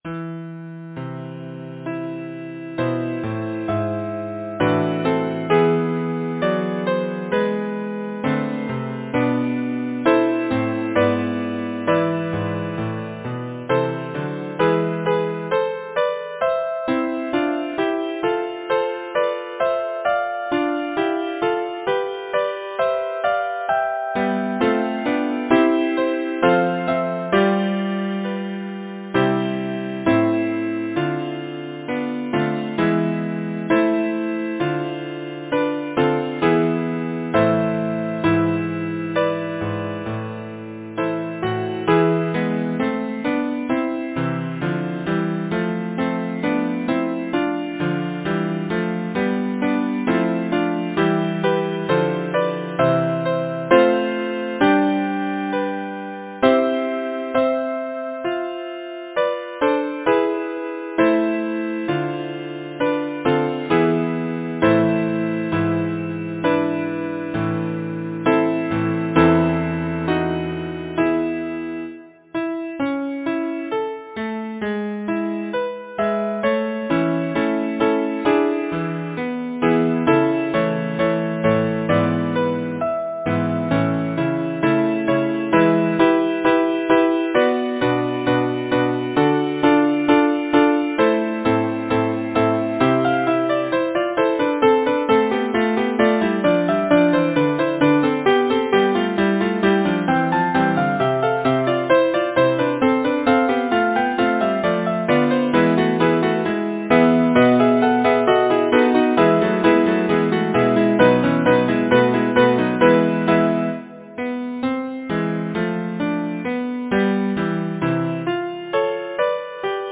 Number of voices: 6vv Voicing: SSATTB Genre: Secular, Partsong, Madrigal
Language: English Instruments: A cappella